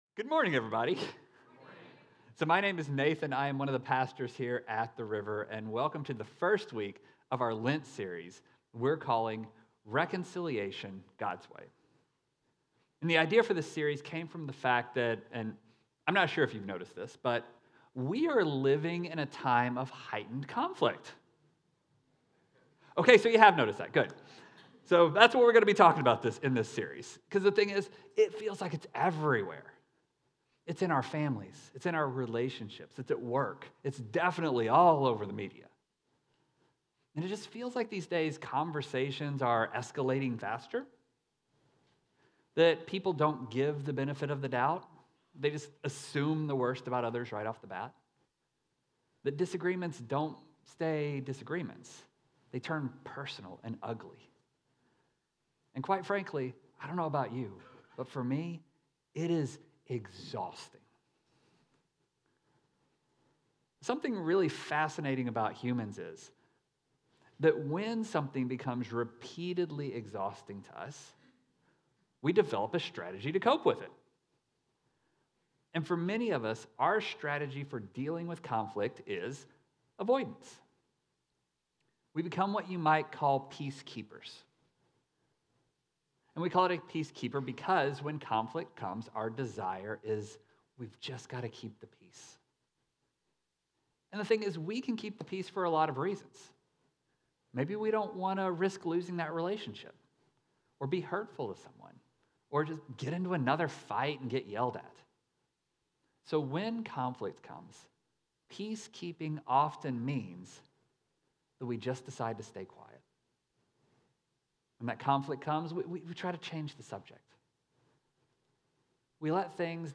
The River Church Community Sermons Reflecting God in Conflict